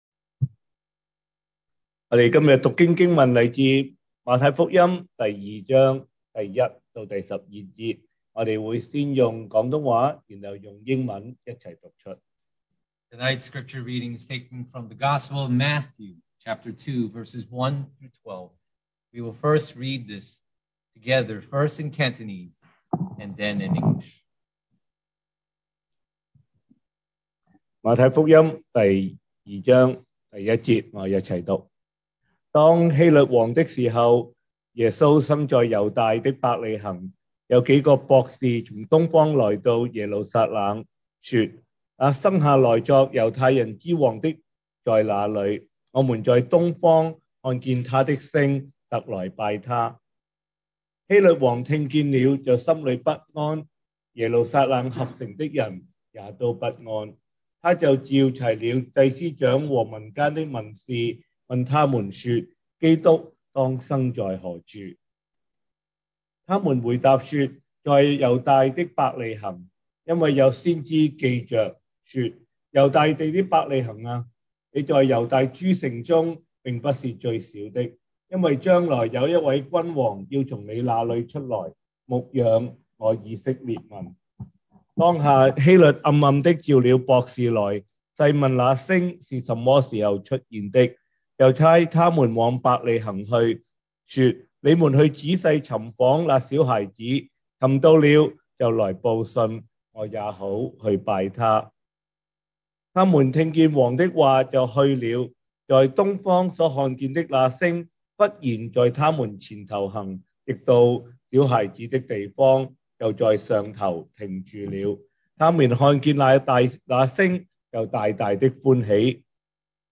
2021 sermon audios
Service Type: Christmas' Eve